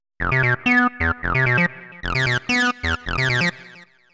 Overdrive -> Filter = meh